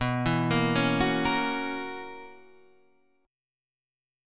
B Major 7th Suspended 2nd Suspended 4th